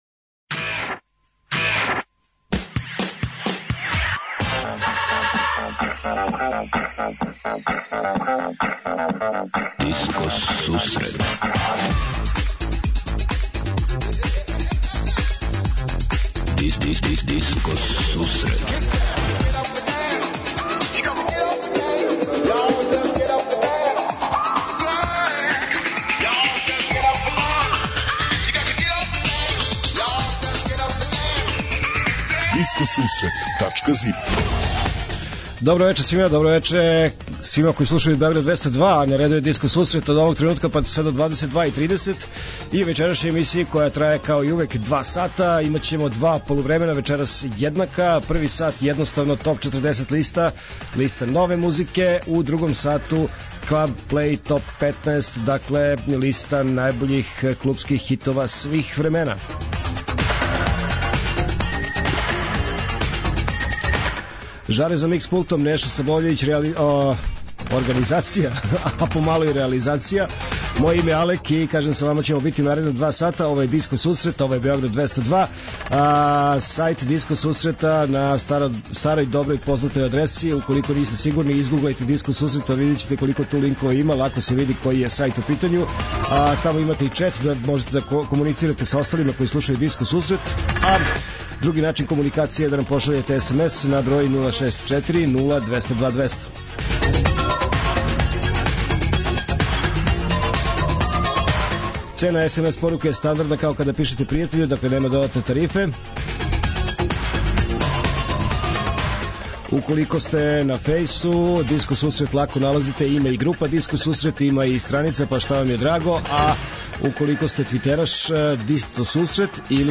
Од 20:30 Диско Сусрет Топ 40 - Топ листа 40 највећих светских диско хитова.